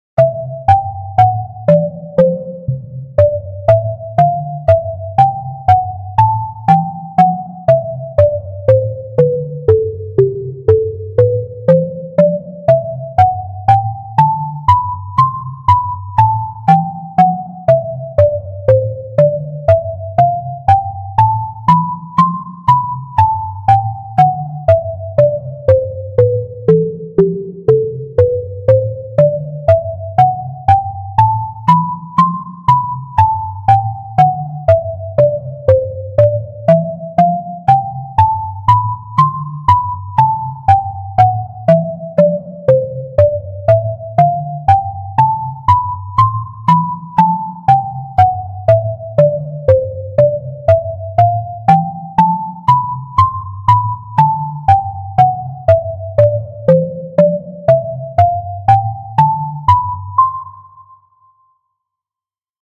水の神殿とか海底ダンジョンとかそんなイメージです。
BPM60 いやし
ゆっくり